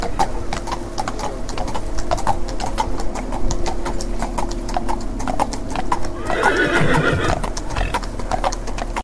Caminhada solidária juntou cerca de 150 pessoas no “Bô Natal, Macedo! Onde a Magia Acontece”
SOM–-Cavalos-1.mp3